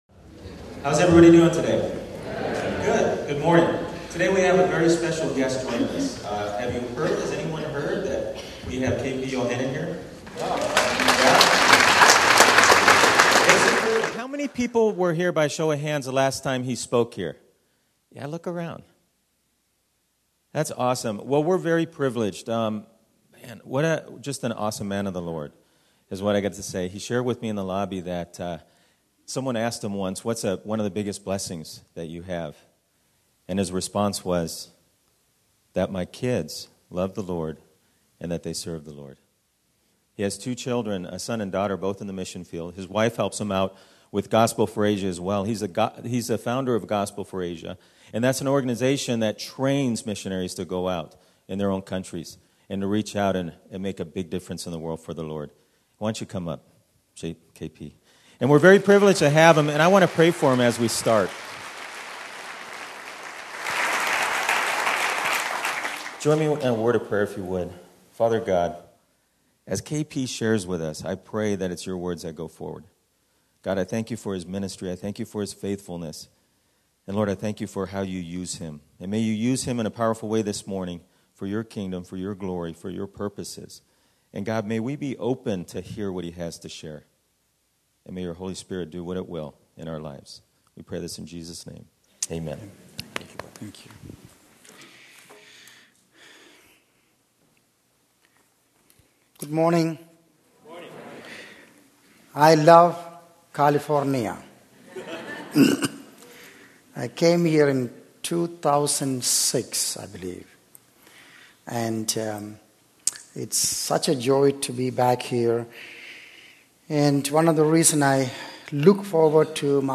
This sermon emphasizes the importance of being willing to sacrifice and serve the Lord wholeheartedly, sharing stories of missionaries and the impact of their dedication. It calls for a deep commitment to prayer, fasting, and supporting native missionaries to spread the gospel globally. The speaker urges listeners to consider the urgency of the harvest, the suffering of persecuted Christians, and the need to prioritize eternal investments over worldly possessions.